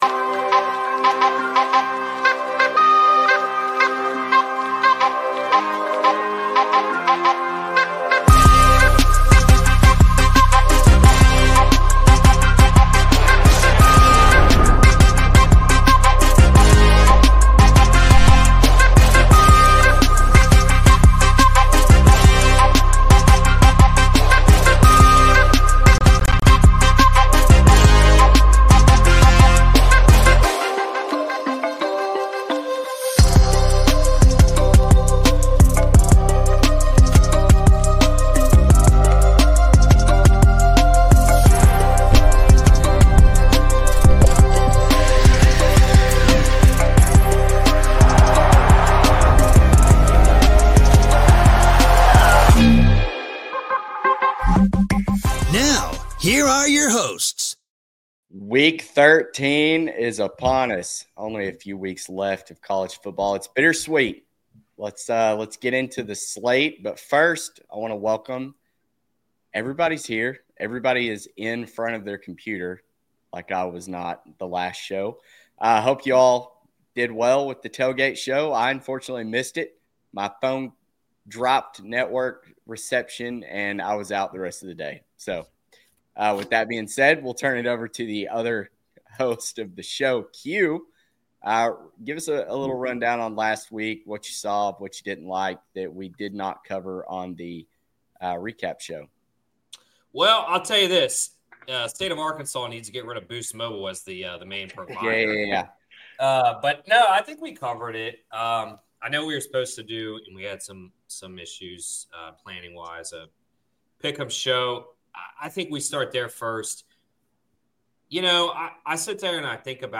Join us for the live stream of the College Football Insiders Show! Whether you’re here for the analytics and trends, the score projections, or just want to hear some dudes talking ball, we’ve got you covered.